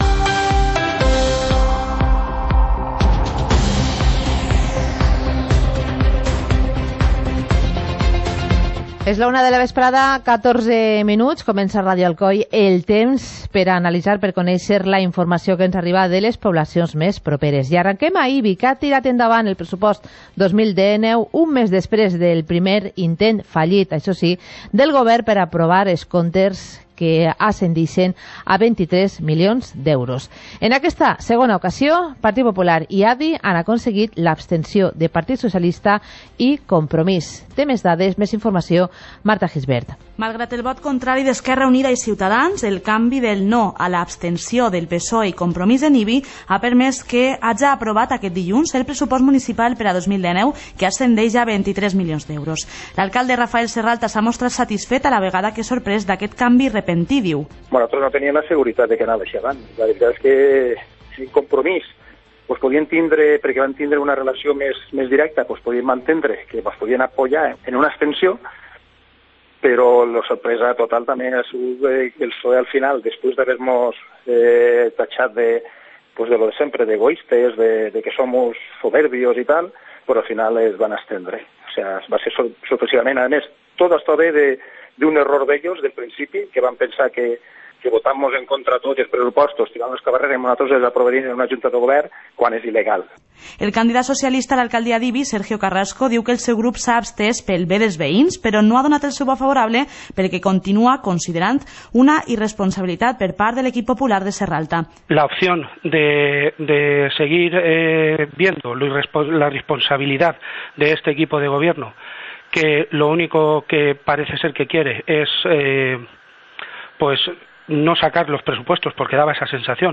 Informativo comarcal - martes, 15 de enero de 2019